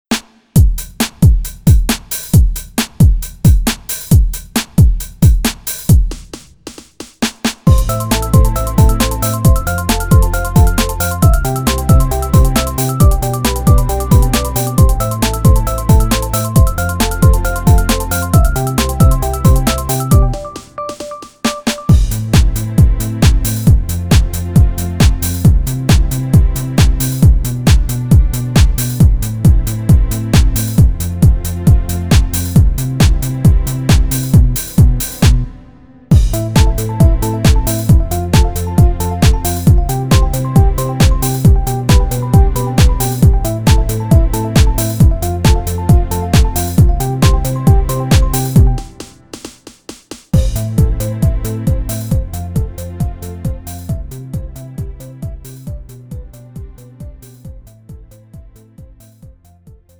음정 (-1키)
장르 가요 구분 Lite MR